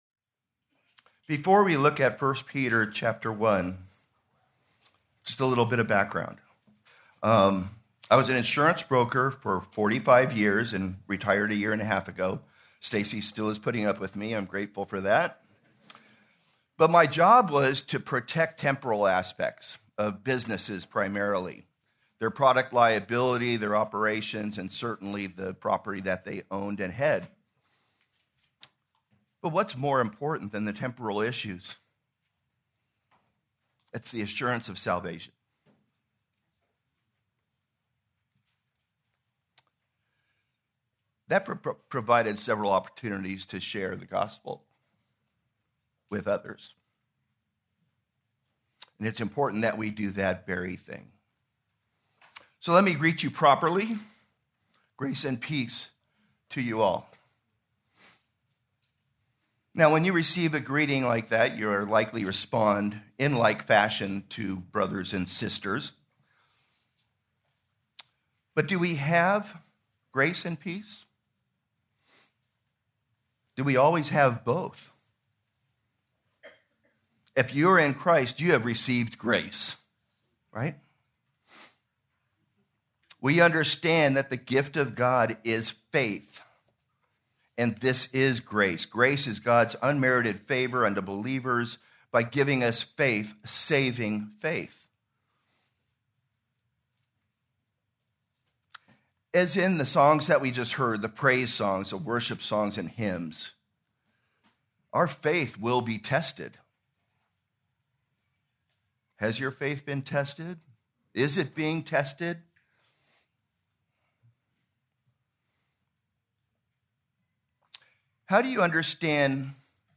March 15, 2026 - Sermon | Anchored | Grace Community Church